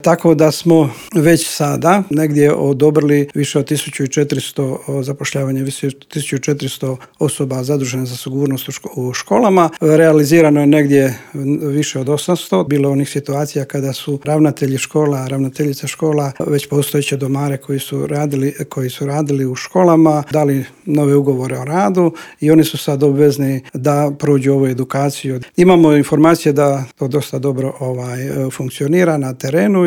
Teme su to o kojima smo u Intervjuu Media servisa razgovarali s ravnateljem Uprave za potporu i unaprjeđenje sustava odgoja i obrazovanja u Ministarstvu obrazovanja Momirom Karinom.